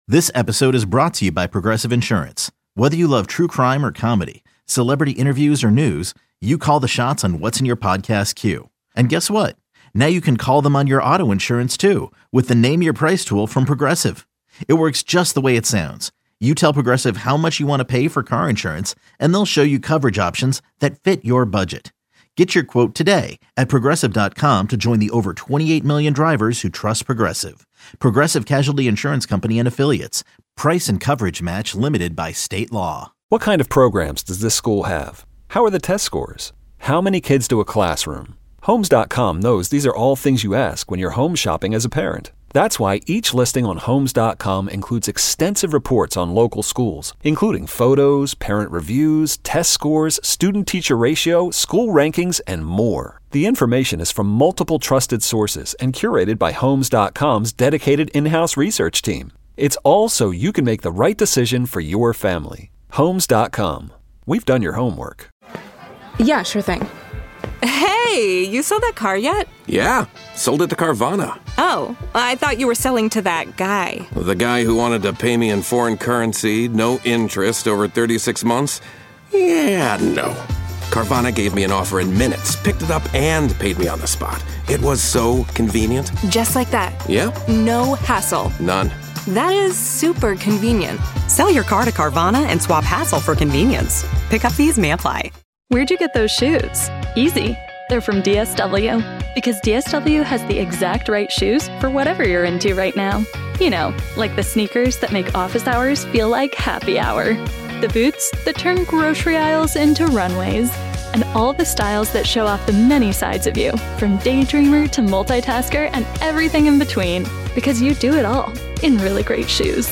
The Sports Junkies - Interviews on WJFK/WTEM: March 3-7